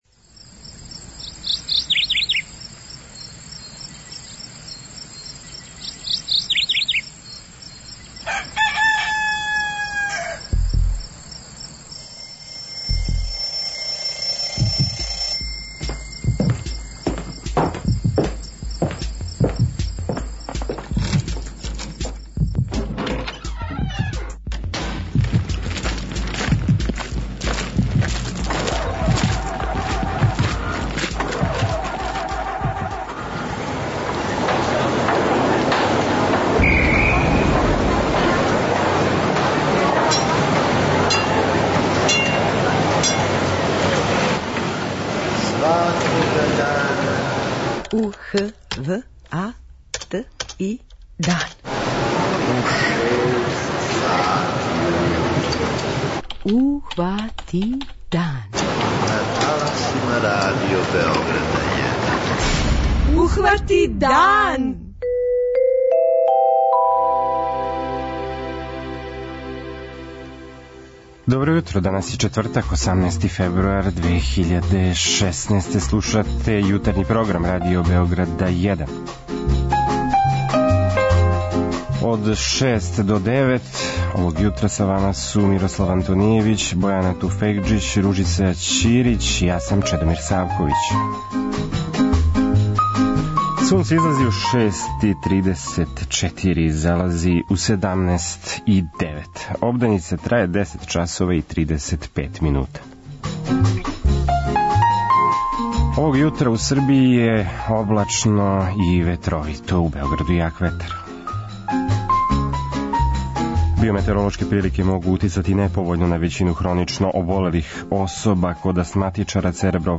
У јутарњем програму чућемо министра туризма у Влади Црне Горе, Бранимира Гвозденовића.